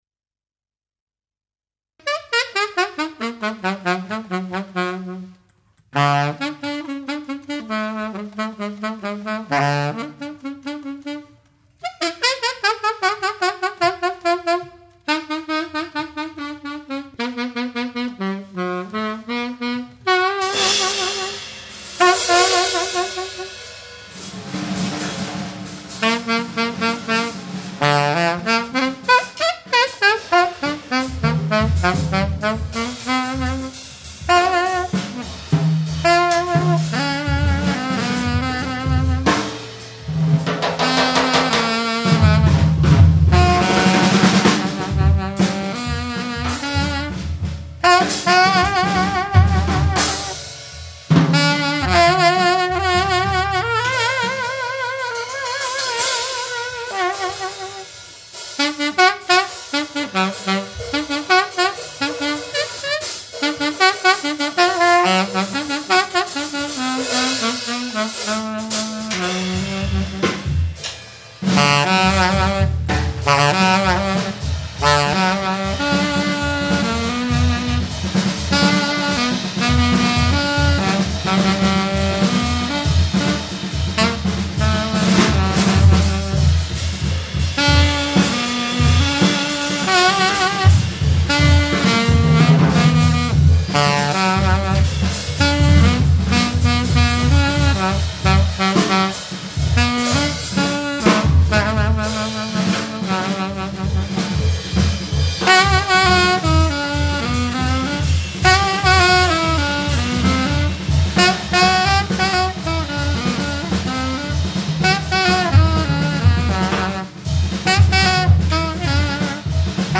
Stockholm, Sweden on March 28, 2000.
hard, free percussion